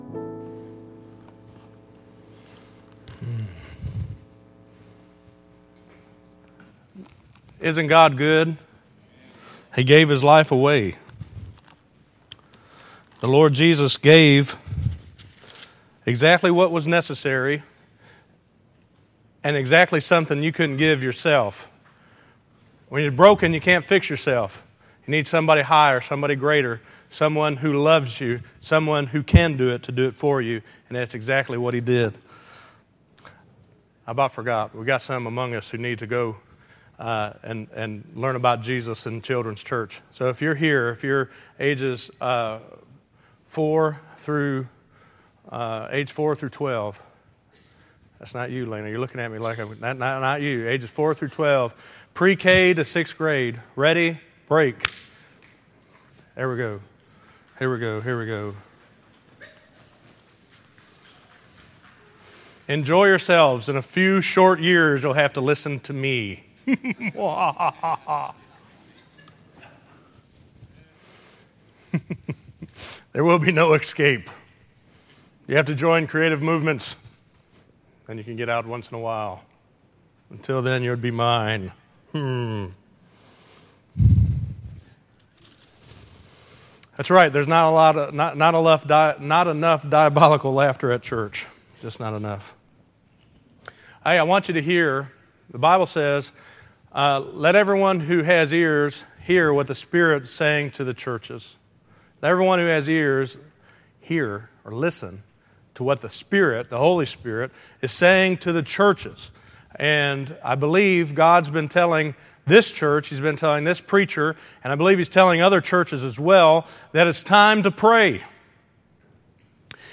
Listen to Prayer Life = Life Prayer - 05_25_2014_Sermon.mp3